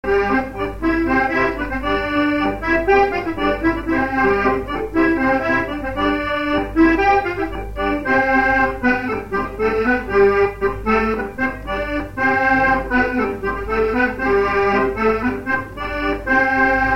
instrumental
danse : branle : courante, maraîchine
Pièce musicale inédite